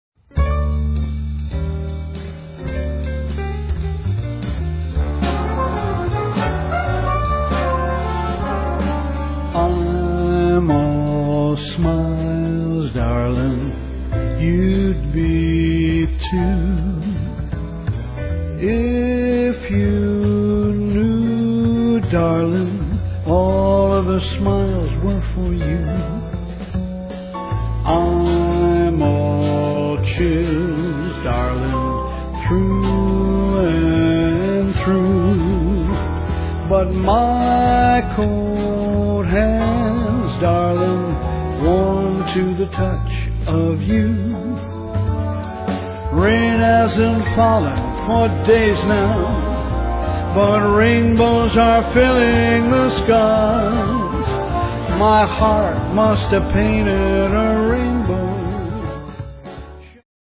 vocals
saxes, piano, trumpet
flute
trombones
bass
drums
strings